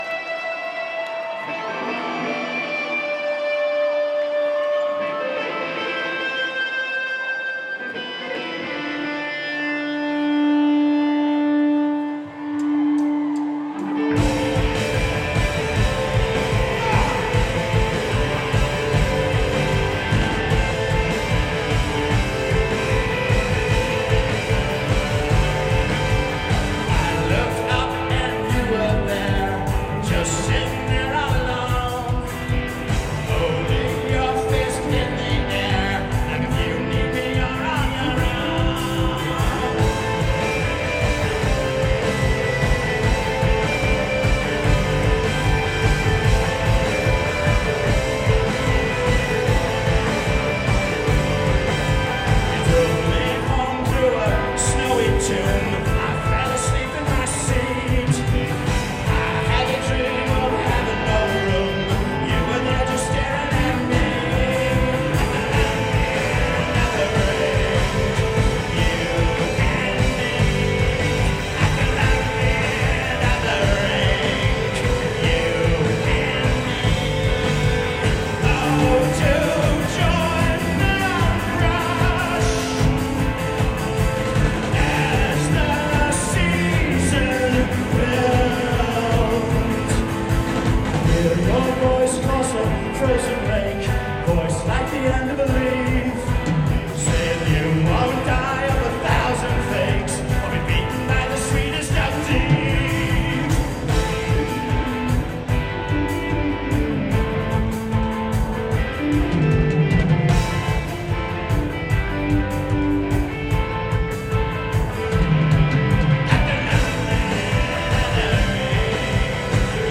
(4th time played live)